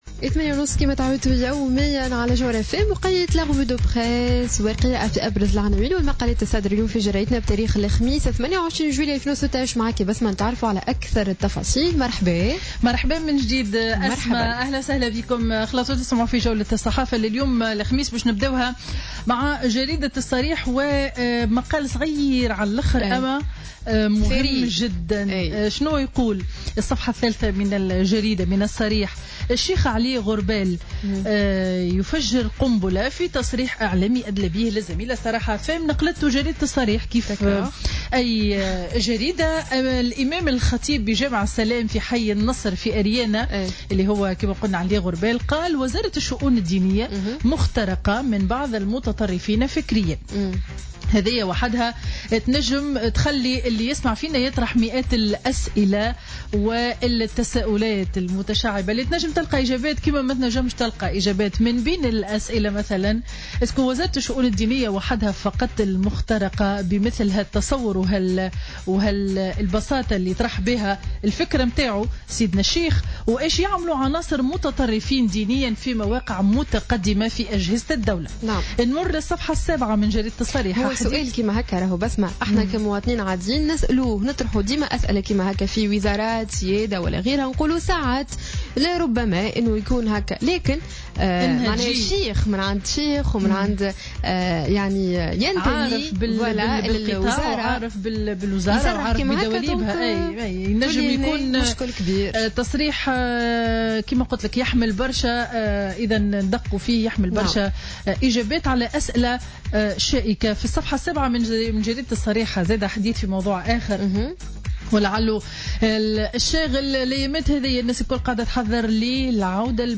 Revue de presse du jeudi 28 juillet 2016